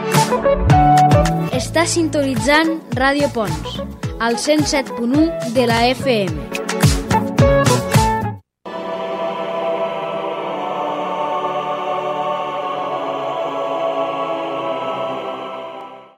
Indicatiu de l'emissora i tema musical